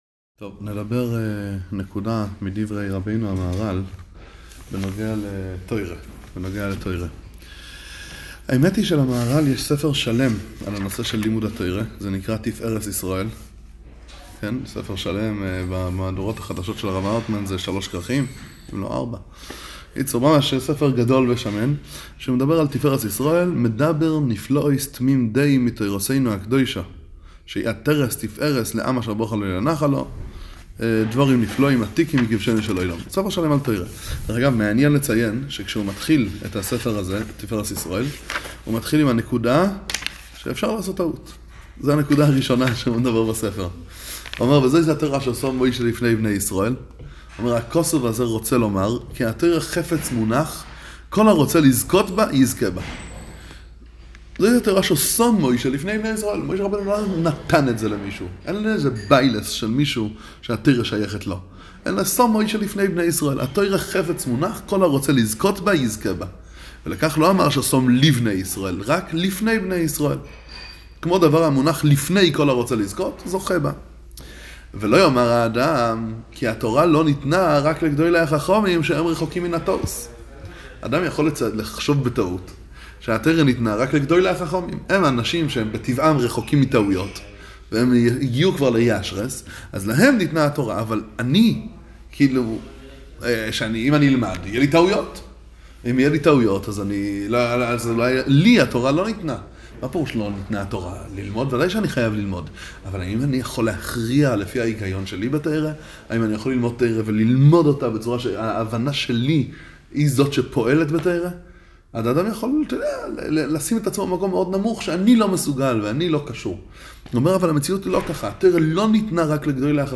נמסר בעיר פראג סמוך לציונו של מהר"ל זי"ע. קצת מדבריו ומקורות חייו. עיון בתורת הנגלה של המהר"ל. הרחבה וביאור של הרעיון בצורה מסודרת, ניתן לצפות בשיעור זה: לימוד ההלכה היושר הפשוט המביא לעולם הבא – נתיב התורה פרק א' – חלק 12
שיעור-מהרל-בפראג-תורה.m4a